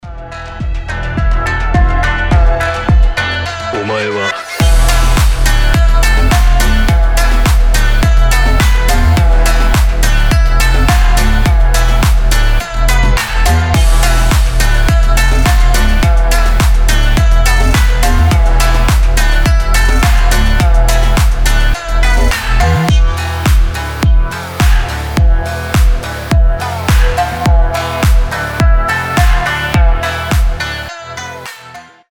• Качество: 320, Stereo
мощные басы
качающие
японские
биты
Чёткий саунд с качем